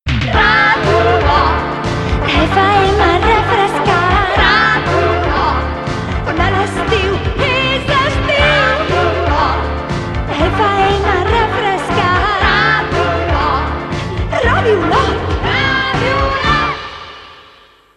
Indicatiu d'estiu